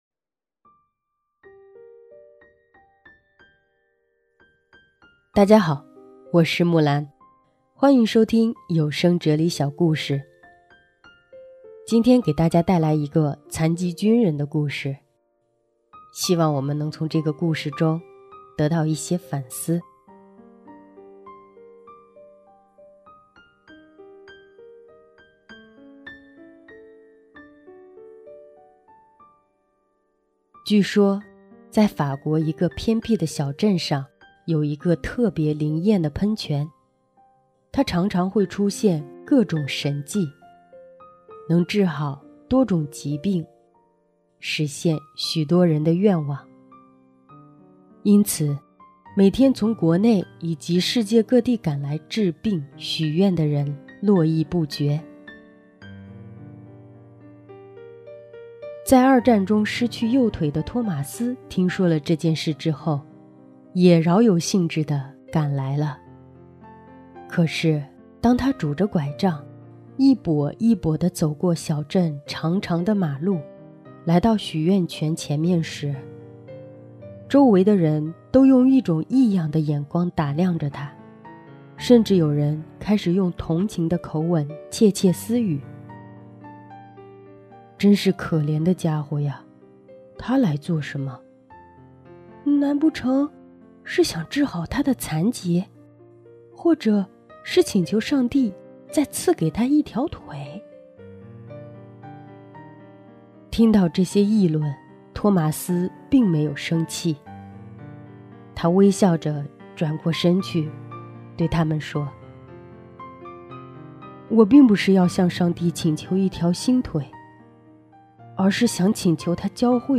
今天故事结尾的歌曲是本届原创圣歌大赛的参赛作品